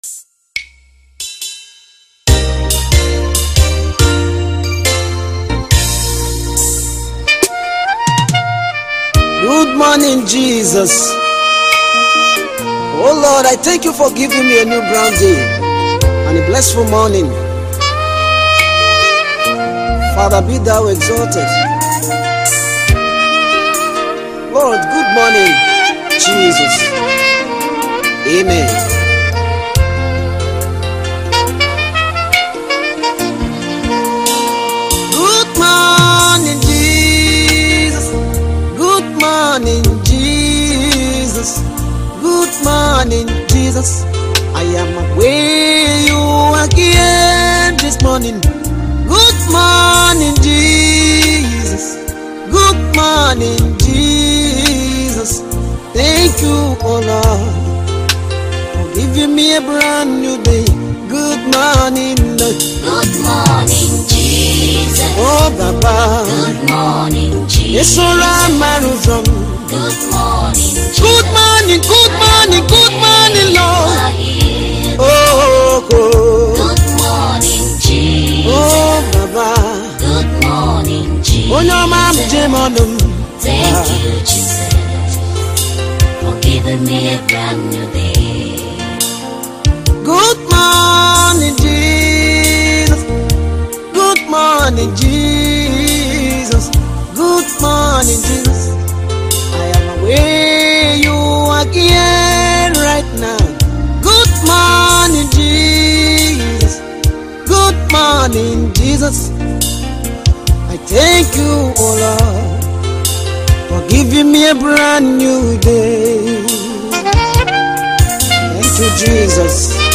Nigerian Igbo Gospel music singer
new worship single